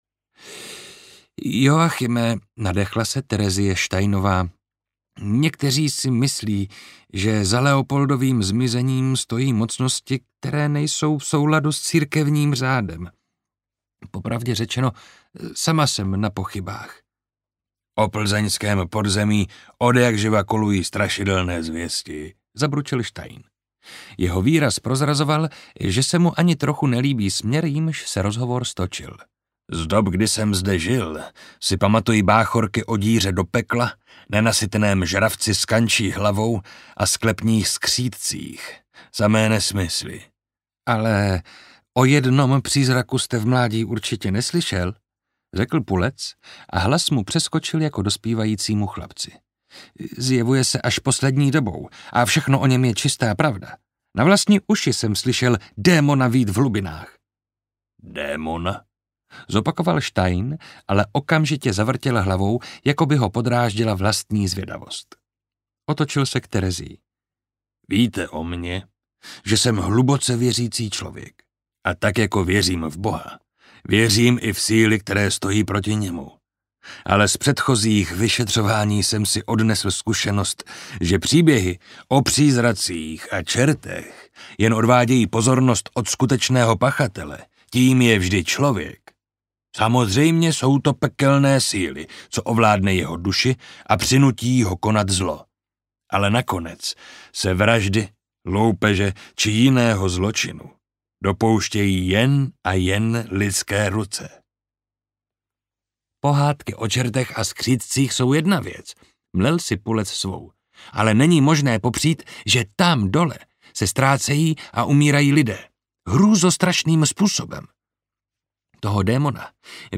Anděl v podsvětí audiokniha
Ukázka z knihy
• InterpretMarek Holý